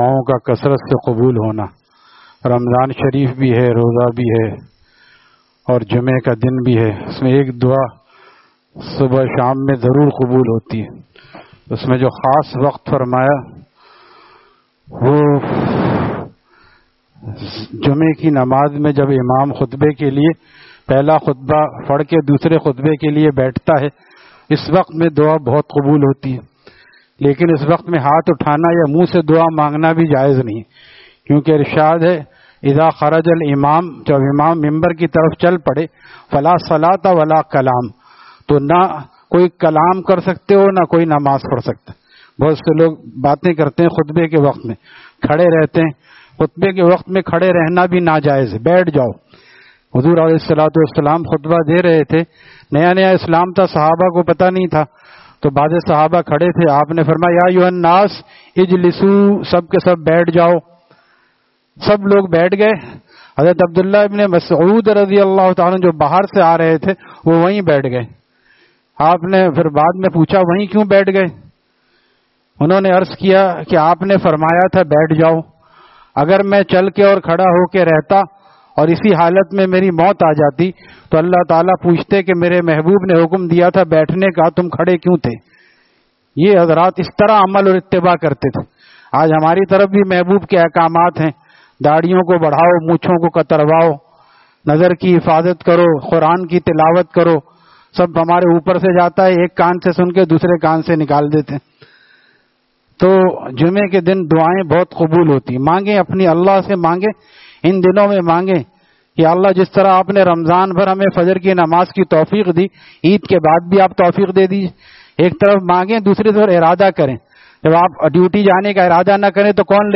DETAILS: Taleem After Fajor at Jamia Masjid Gulzar e Muhammadi, Khanqah Gulzar e Akhter, Sec 4D, Surjani Town